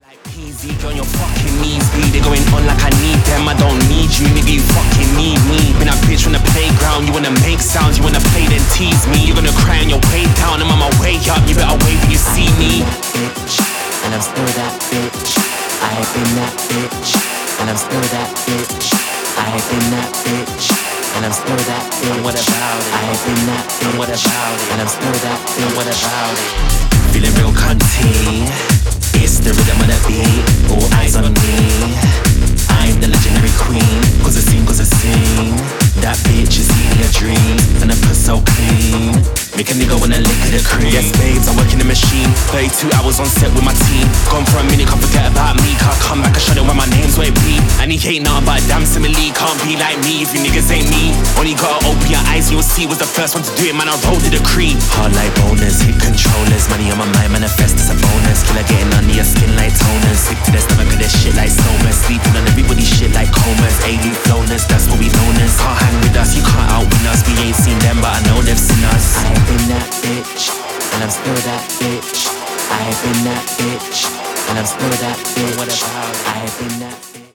Techno Bass Sale Wave